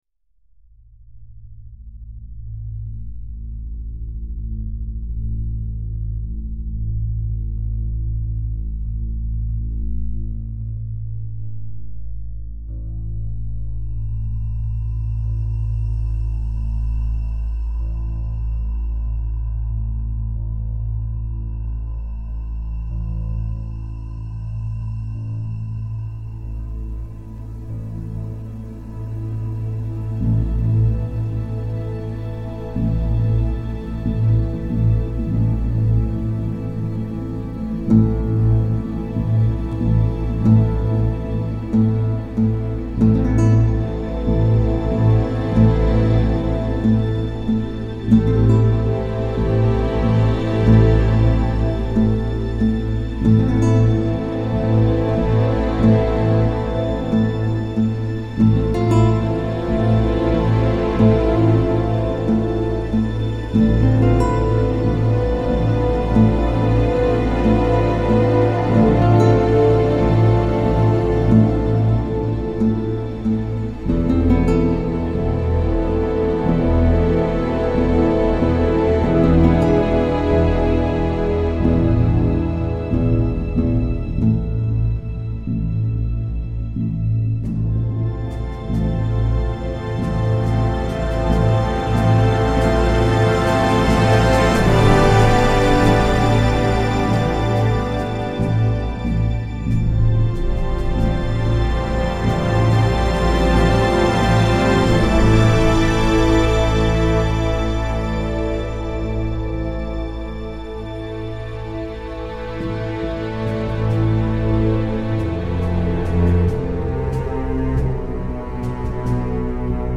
Un score toujours plus bruyant et toujours plus violent !